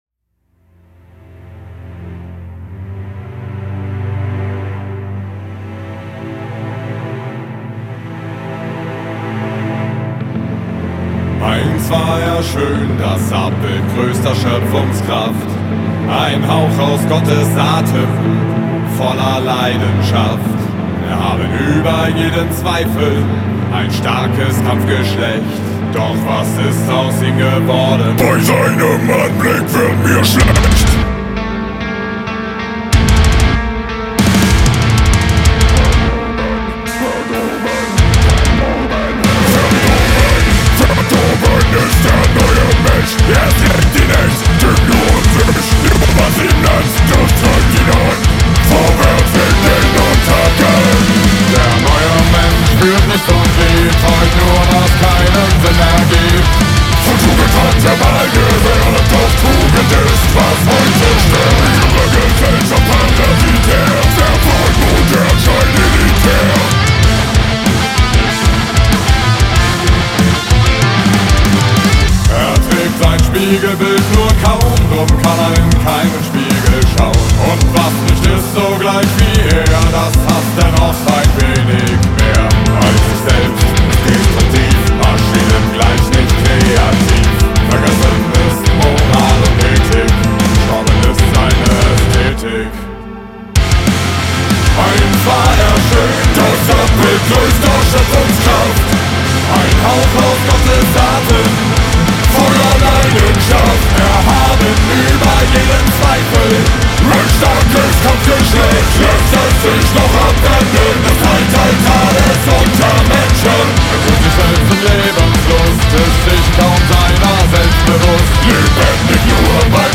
progressiver Musik